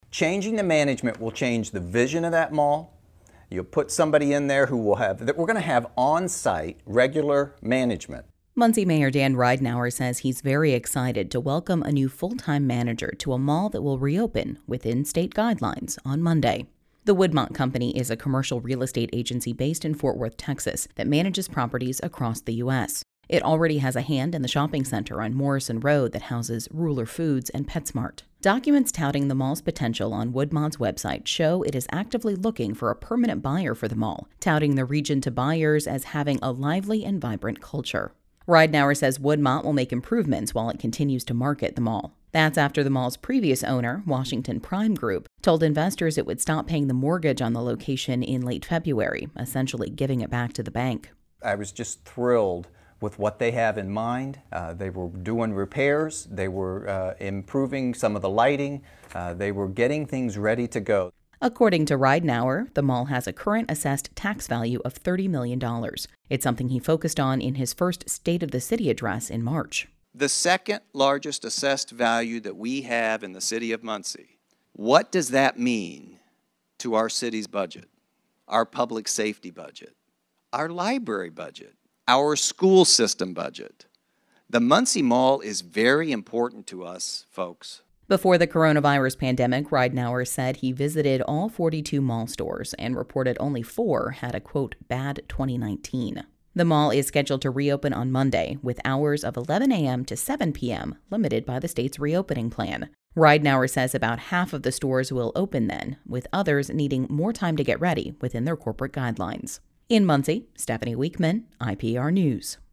Muncie Mayor Dan Ridenour says he’s very excited to welcome a new full-time manager to a mall that will reopen, within state guidelines, on Monday.